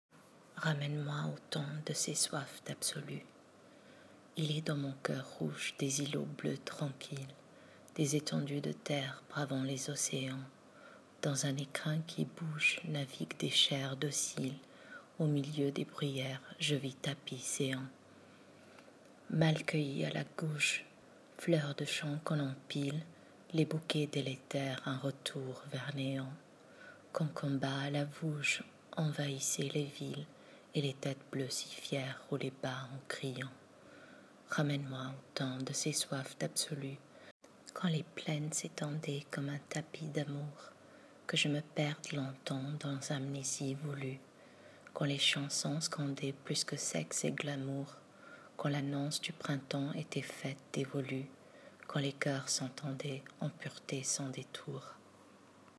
Lecture du poème: